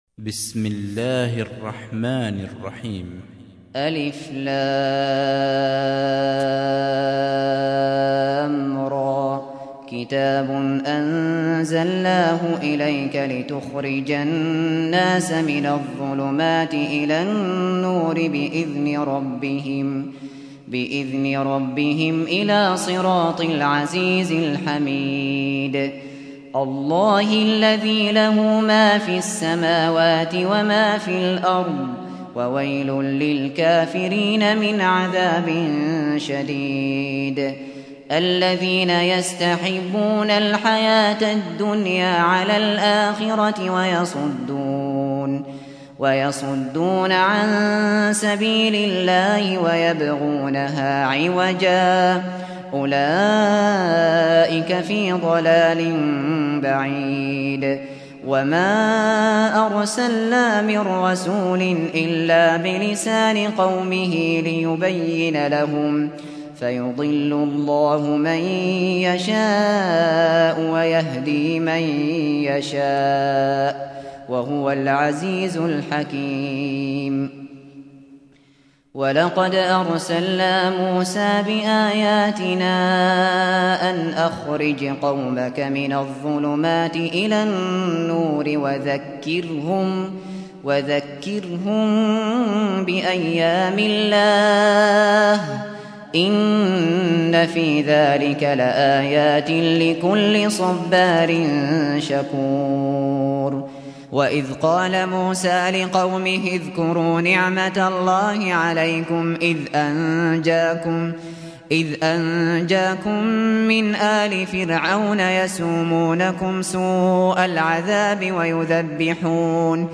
سُورَةُ ابراهيم بصوت الشيخ ابو بكر الشاطري